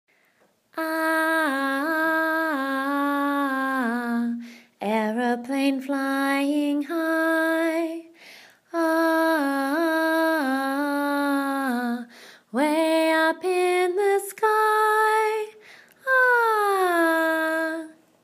Ah Sound - Aeroplane